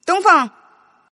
Index of /client/common_mahjong_tianjin/mahjongjinghai/update/1124/res/sfx/tianjin/woman/